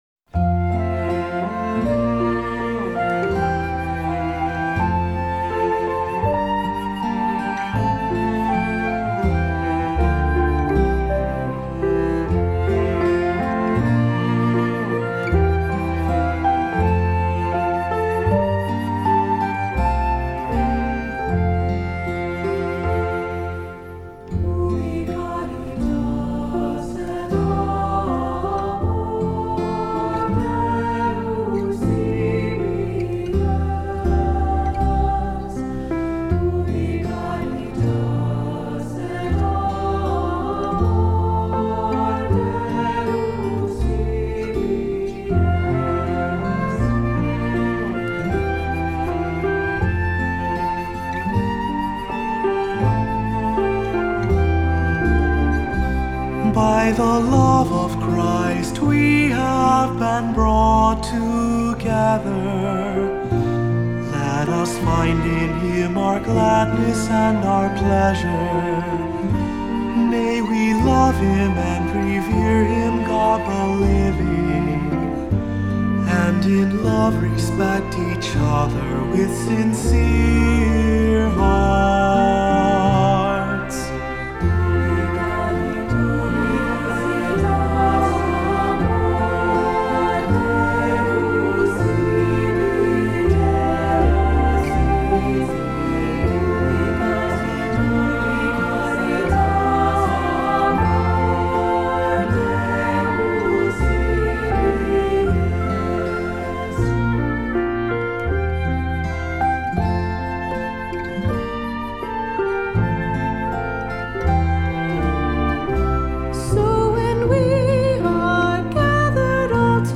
Voicing: Cantor,Assembly,2-part Choir,SATB